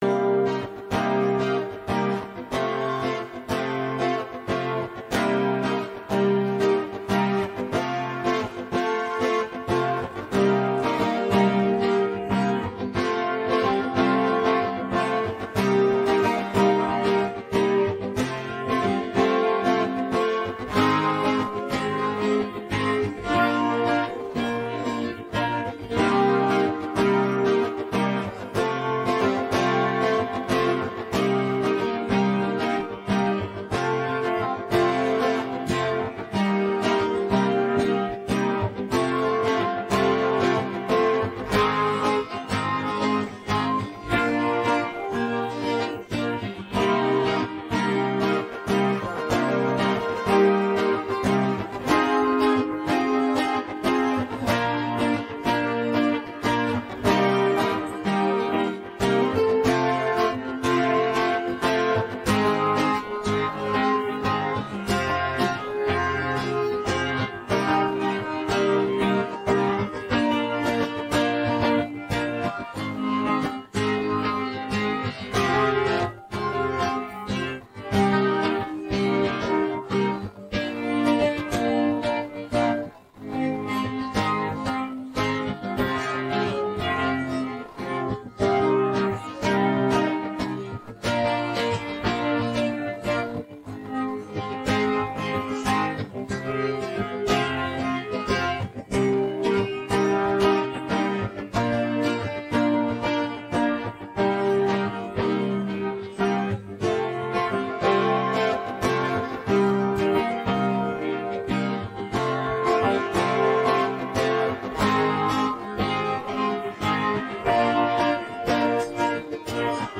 це енергійний трек